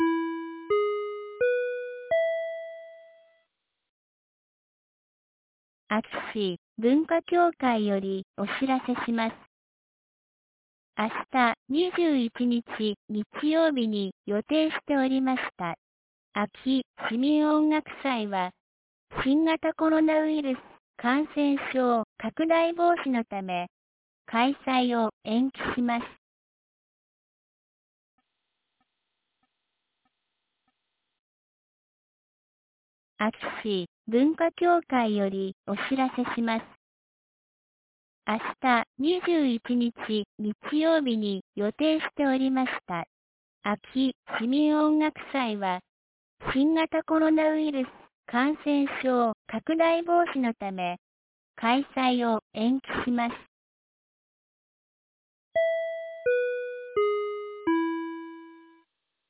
2022年08月20日 12時16分に、安芸市より全地区へ放送がありました。